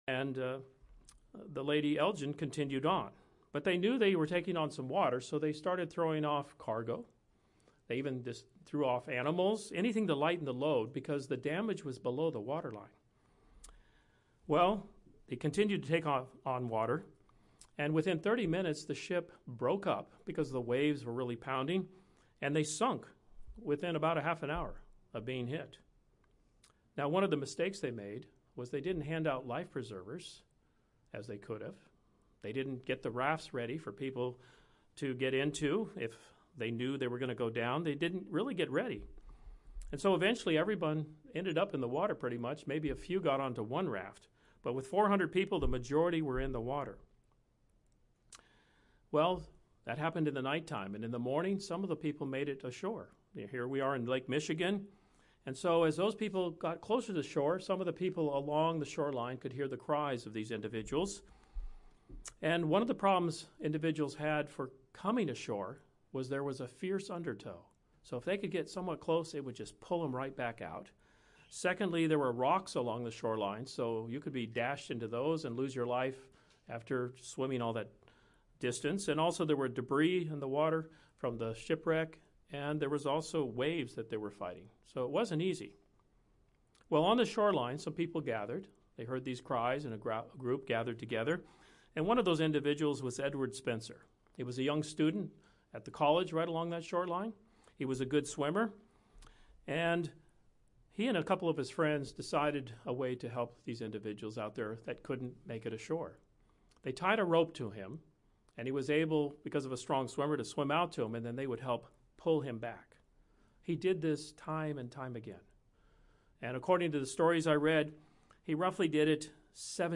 This sermon shares four points on how to improve in serving God and man as we look forward to His coming kingdom.